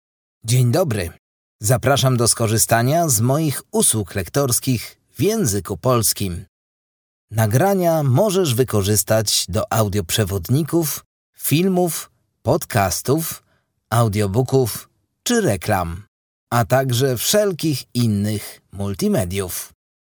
Polish male voice overs.
Polish voice over